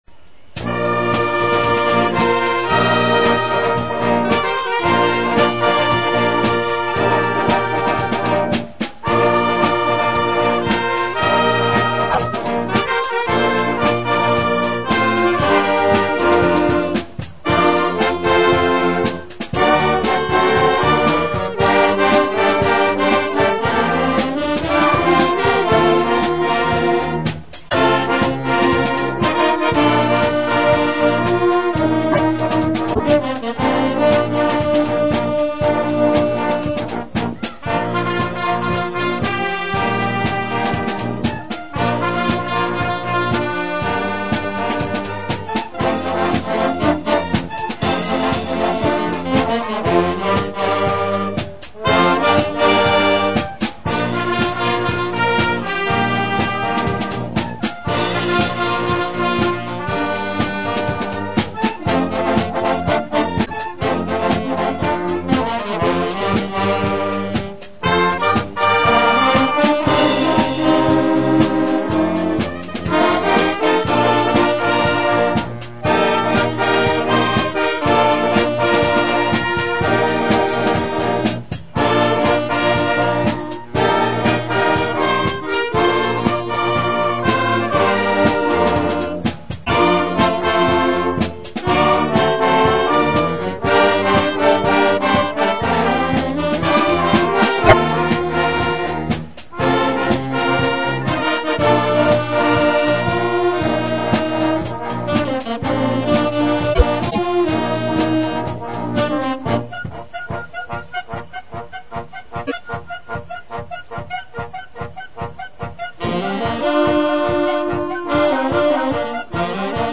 Poslušajte neke skladbe u našoj izvedbi: